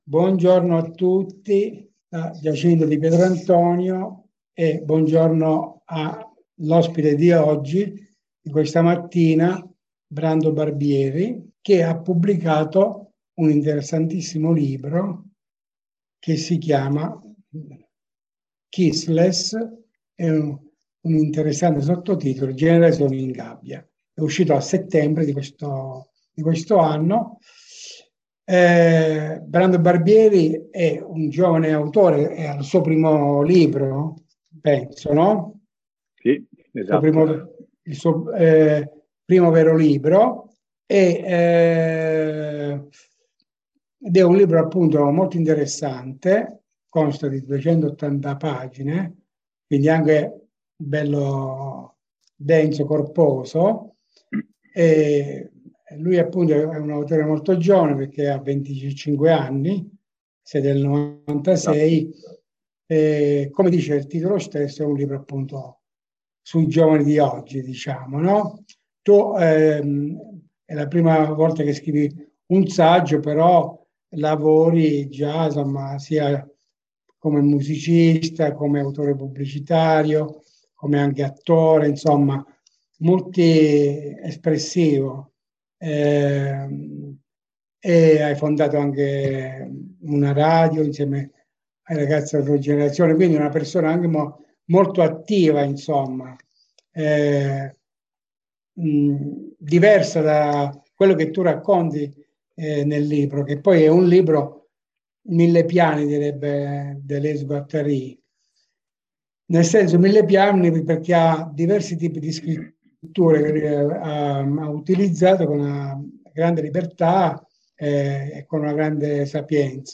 Rubrica ideata e condotta da Giacinto di Pietrantonio su libri di, per e introno all’arte.